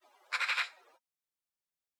birdcall_07.ogg